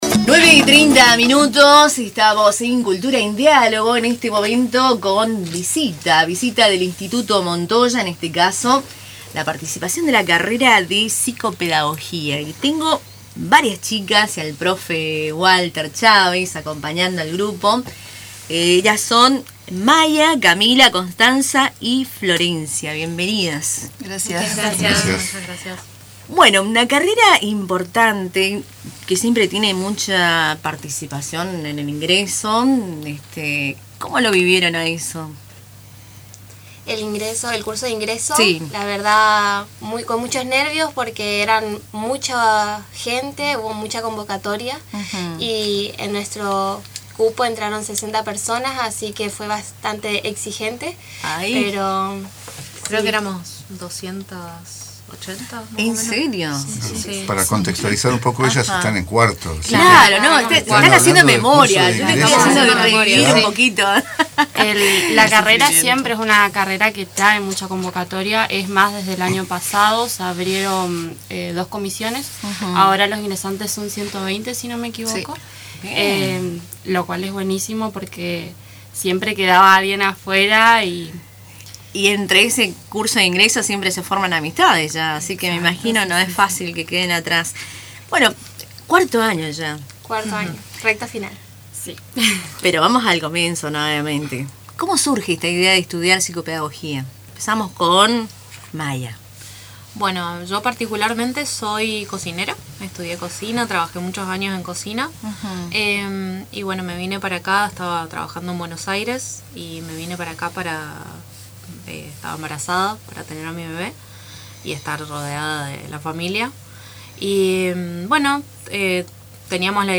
programa radial Cultura en diálogo